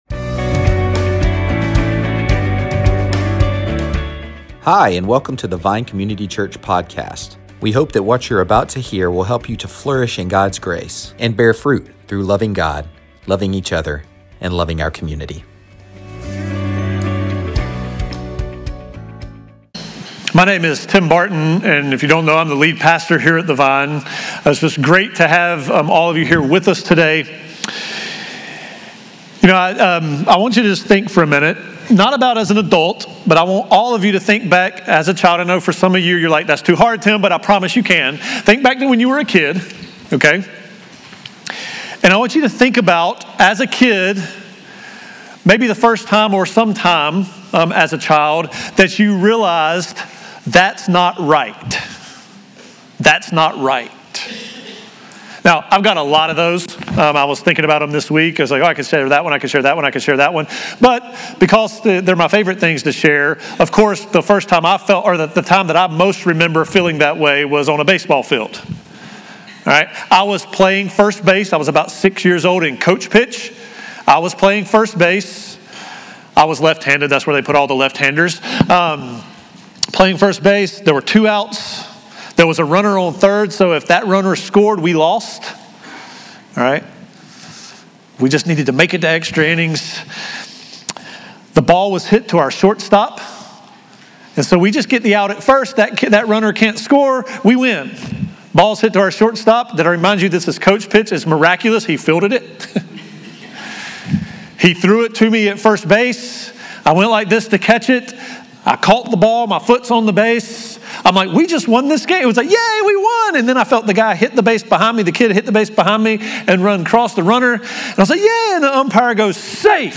Sermons |